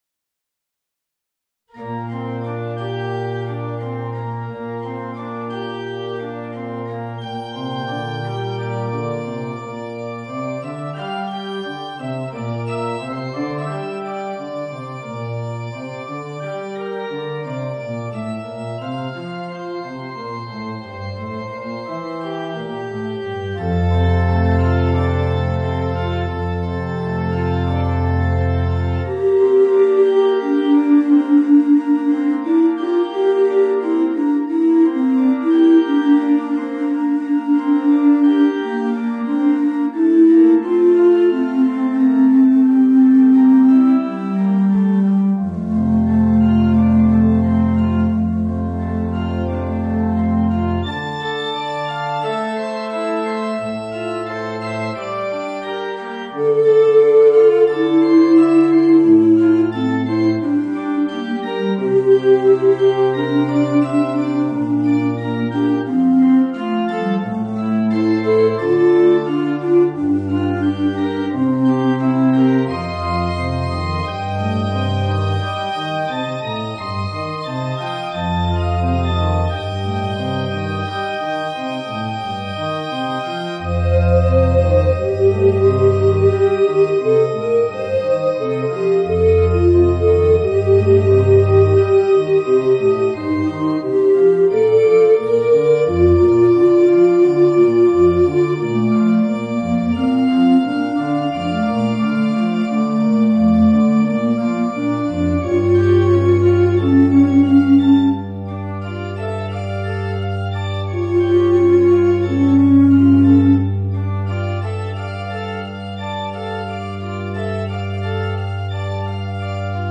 Voicing: Bass Recorder and Organ